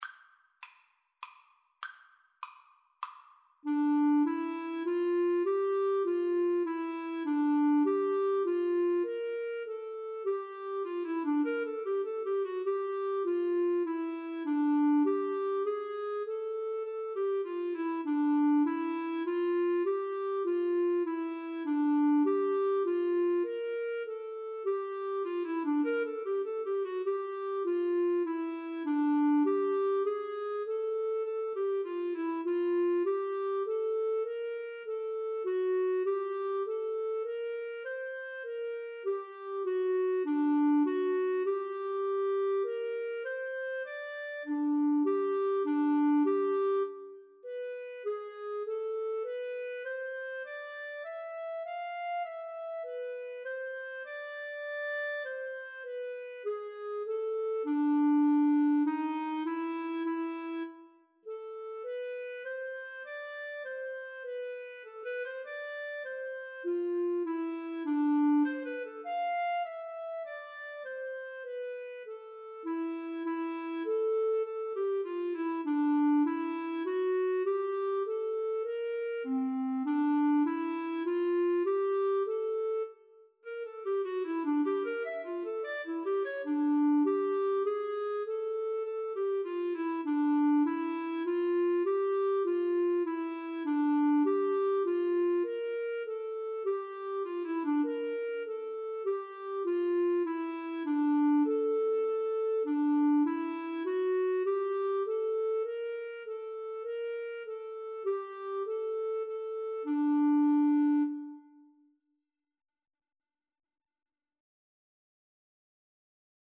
Tempo di menuetto
3/4 (View more 3/4 Music)
Classical (View more Classical Flute-Clarinet Duet Music)